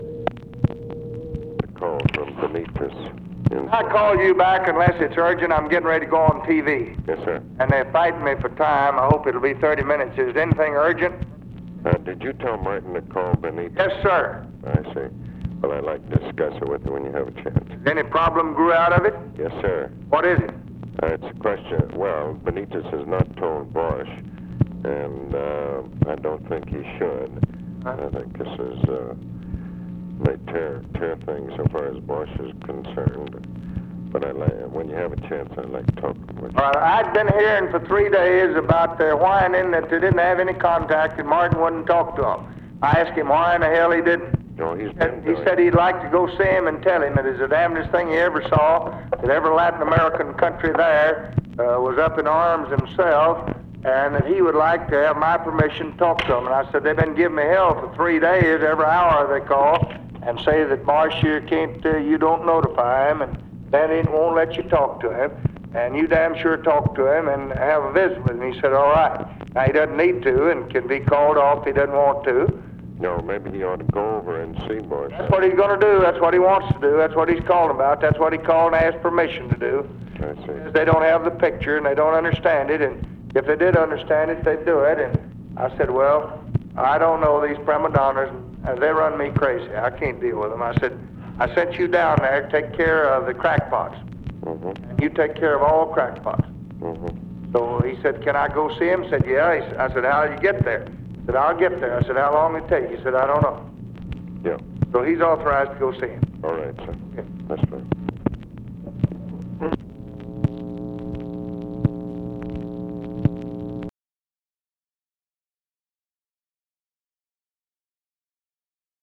Conversation with ABE FORTAS, May 3, 1965